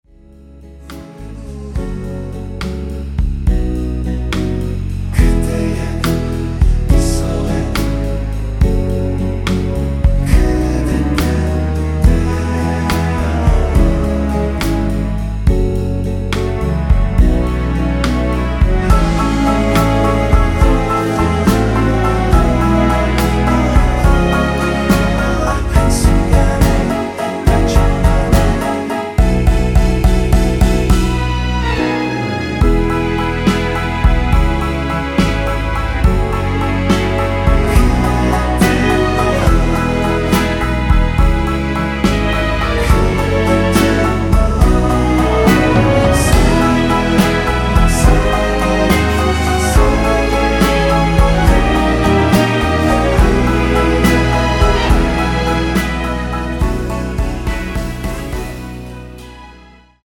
원키에서(-2)내린 코러스 포함된 MR입니다.
앞부분30초, 뒷부분30초씩 편집해서 올려 드리고 있습니다.
중간에 음이 끈어지고 다시 나오는 이유는